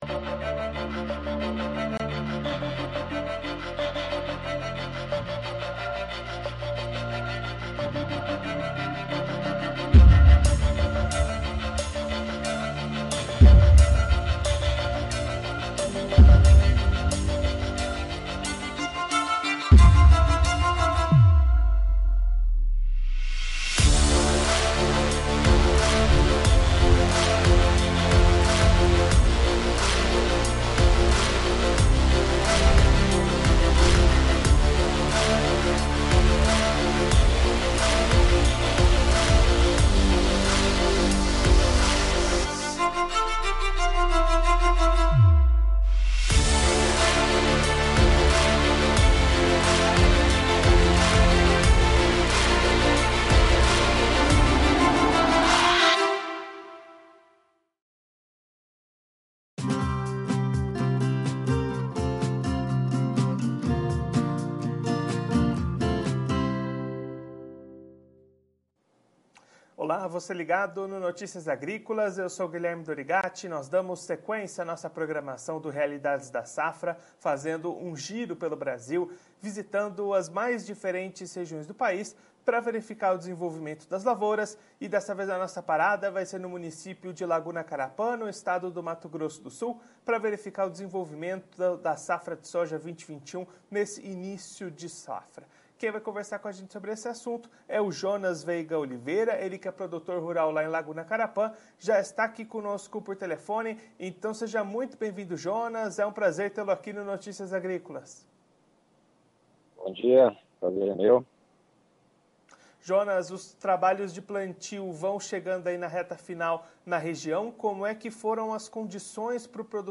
Confira a entrevista completa com o produtor rural de Laguna Carapã/MS.